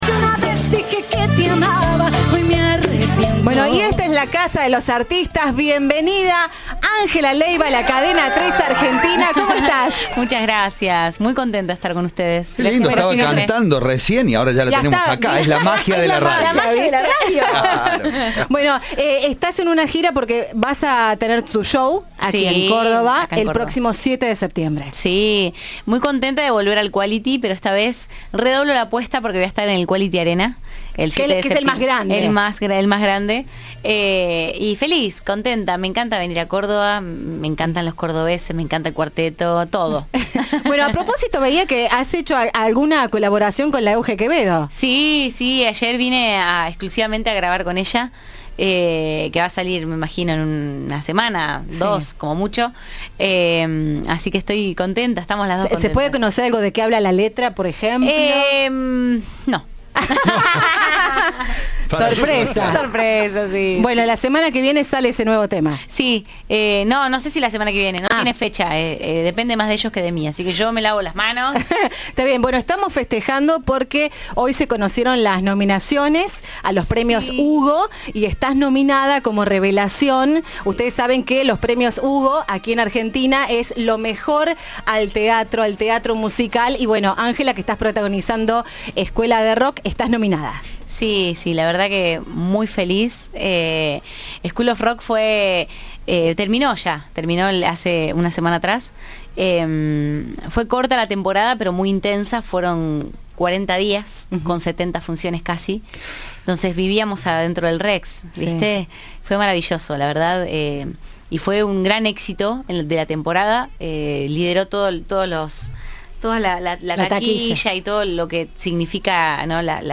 La reconocida cantante visitó los estudios de Cadena 3 en la previa de su presentación el 7 septiembre. Habló de su vínculo con el cuarteto y del éxito del musical Escuela de Rock.